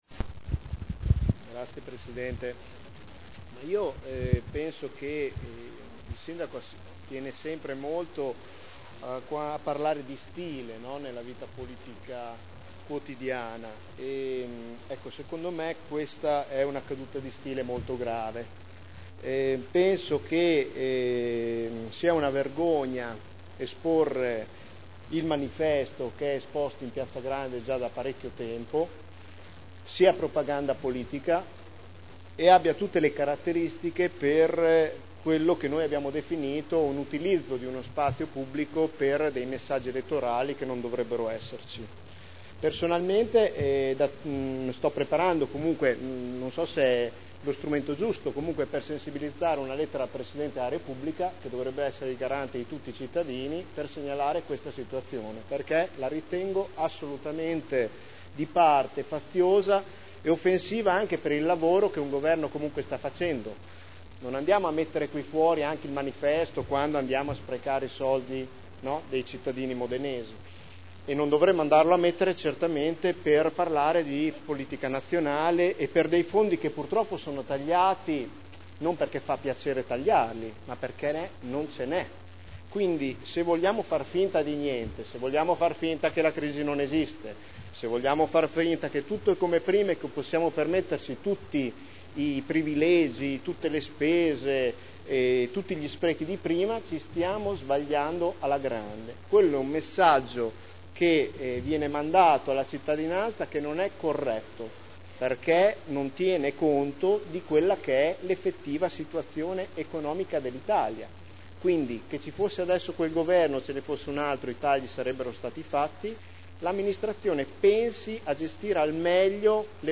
Seduta del 3/02/2011.